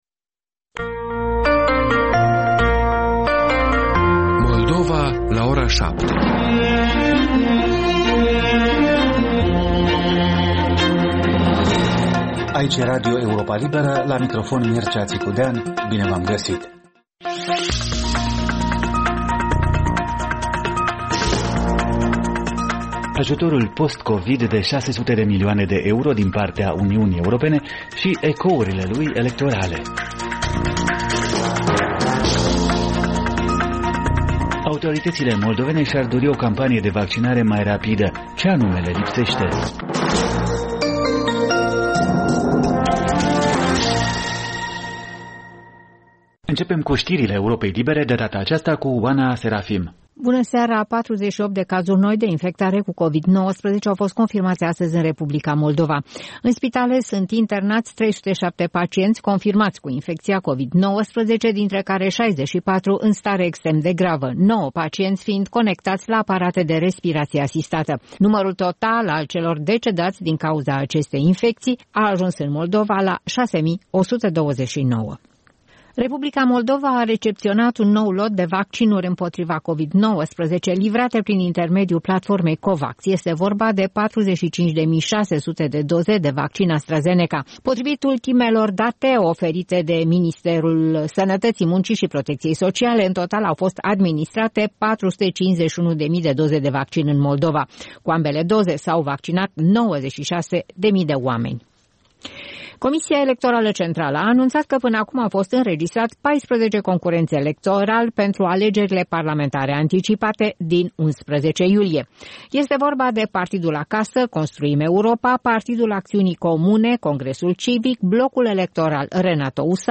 Ştiri, interviuri, analize şi comentarii. În fiecare vineri, rubrica „Dicționar European”.